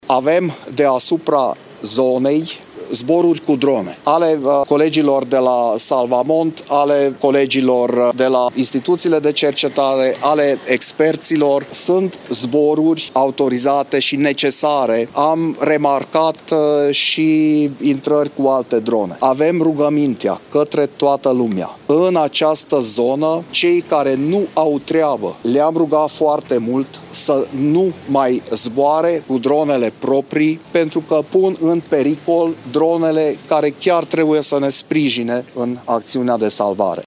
Zona în care s-au produs surpările e supravegheată permanent de drone, a mai spus prefectul județului Harghita